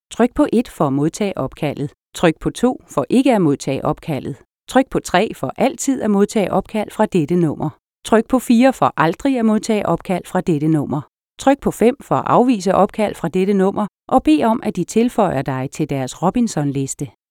Female Danish Asterisk Voice Prompt
Accept Call Navigation.mp3